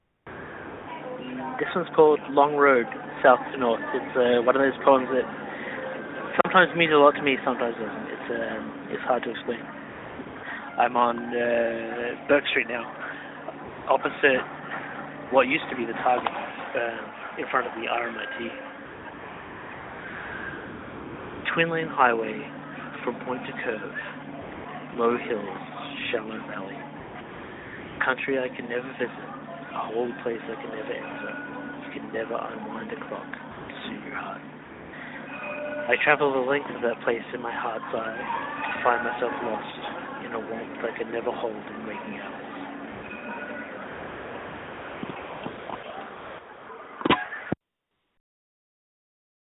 and now  I have; LOWWIRE is  a collection of poems  by me read
into payphones, I hope you enjoy.